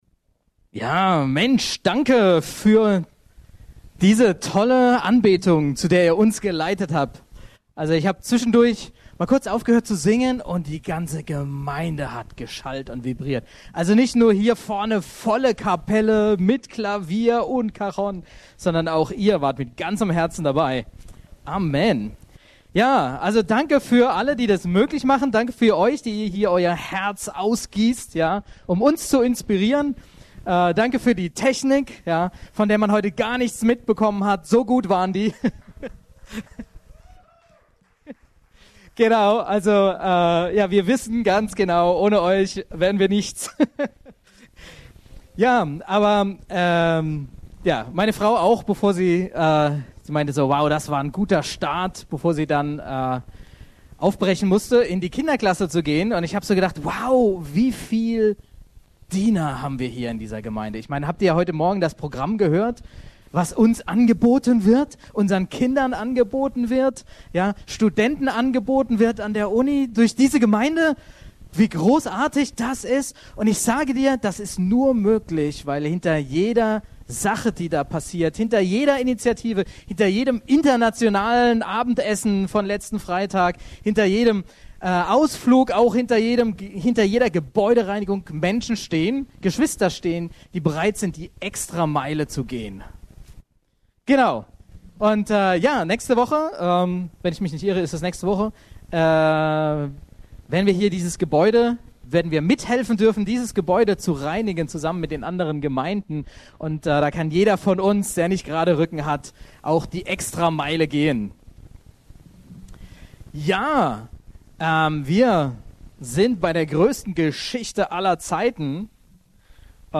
E-Mail Details Predigtserie